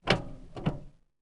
car-steer-1.ogg